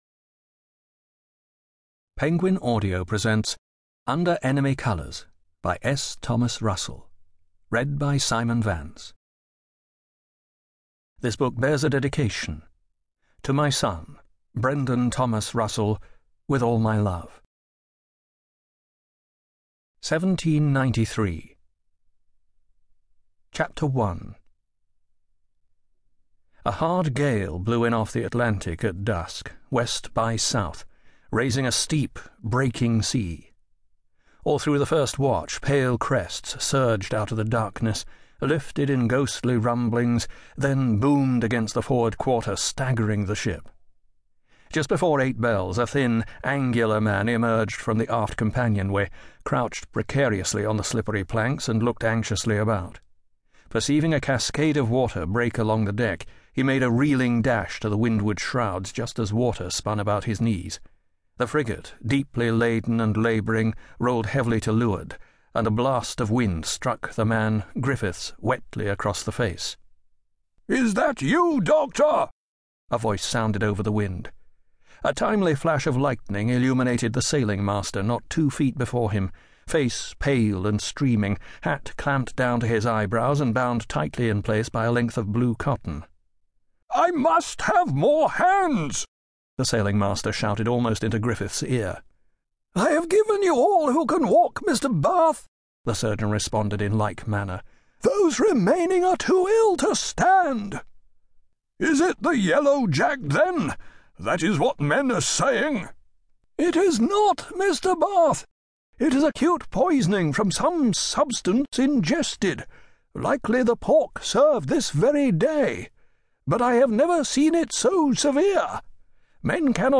Audiobook FormatCD Unabridged
under_enemy_colours_audiobook_sample.mp3